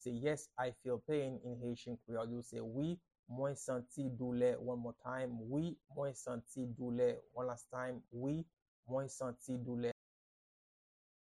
Pronunciation and Transcript:
Yes-I-feel-pain-in-Haitian-Creole-Wi-mwen-santi-doule-pronunciation-by-a-Haitian-teacher.mp3